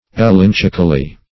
elenchically - definition of elenchically - synonyms, pronunciation, spelling from Free Dictionary Search Result for " elenchically" : The Collaborative International Dictionary of English v.0.48: Elenchically \E*len"chic*al*ly\, adv. By means of an elench.